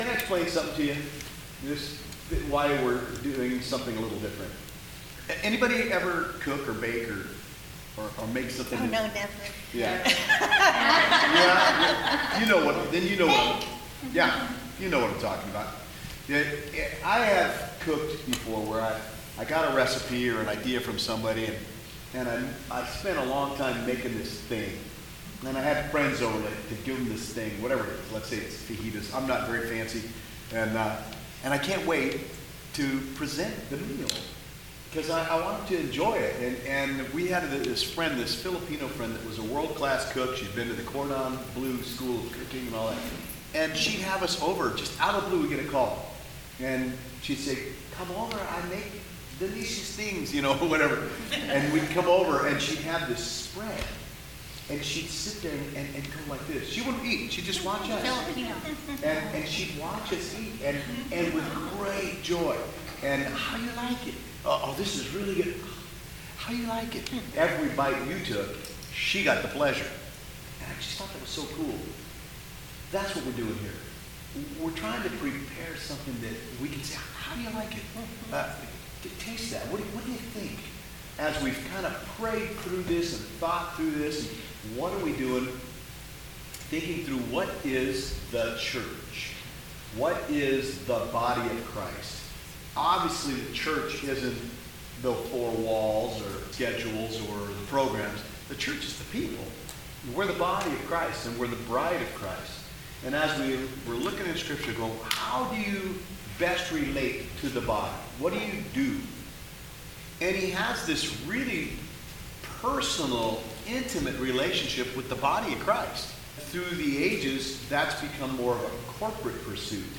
This is the first church in the round with a new approach to a more intimate, organic style of doing a church service. We are all sitting in a circle with no "sermon" per se.
This is a communion service as well. The audio is via some overhead microphones rather than lapel mics and a pulpit.